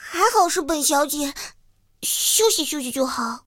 T-60中破修理语音.OGG